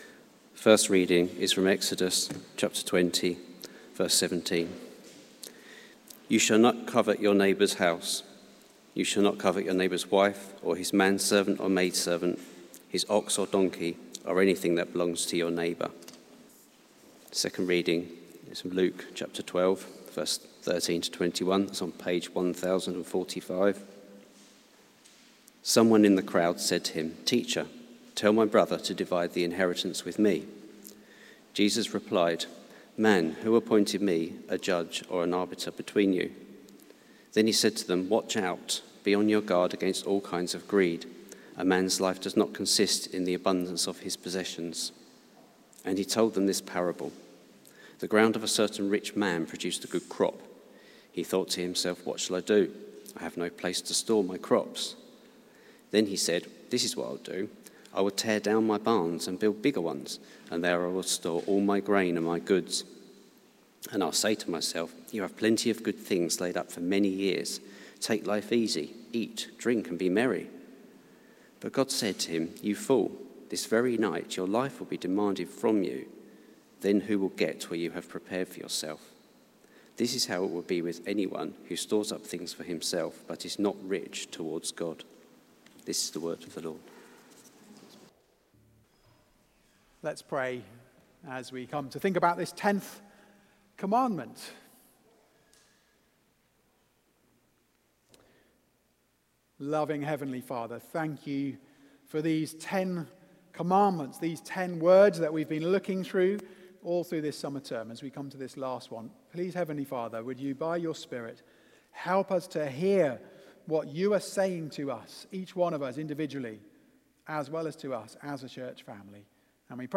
Series: The Ten Commandments Theme: Contentment over Covetousness: Heart Lessons from the Law Sermon